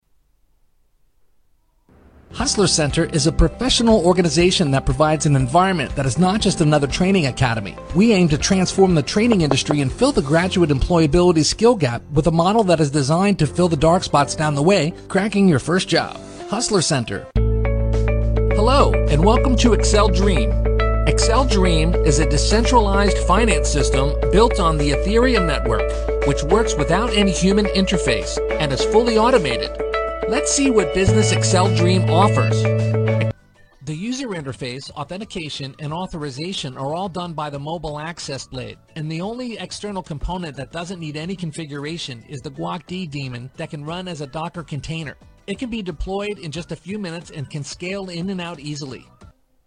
英语配音美式英语配音
• 男英5 美式英语 各风格汇总 激情激昂|沉稳|娓娓道来|科技感|积极向上|时尚活力|神秘性感|素人